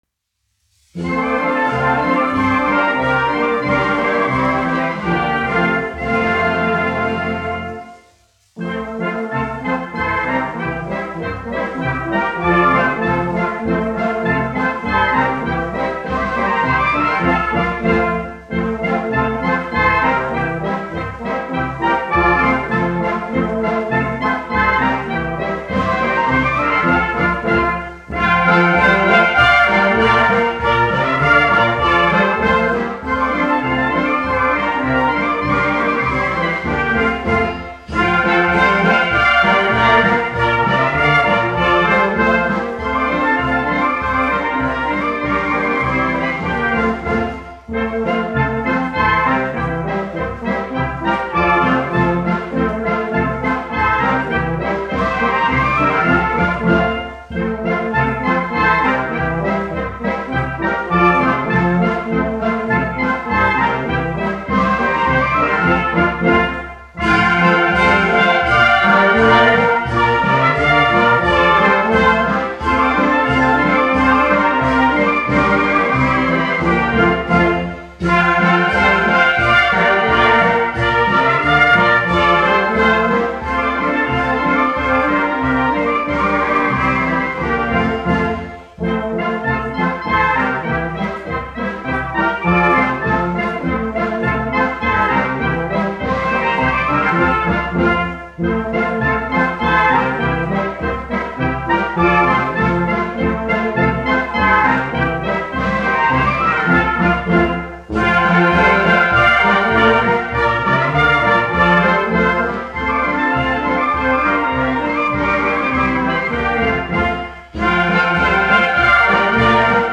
Gustav Ernesaks, 1908-1993, aranžētājs
1 skpl. : analogs, 78 apgr/min, mono ; 25 cm
Tautas dejas, igauņu
Pūtēju orķestra mūzika, aranžējumi
Skaņuplate
Latvijas vēsturiskie šellaka skaņuplašu ieraksti (Kolekcija)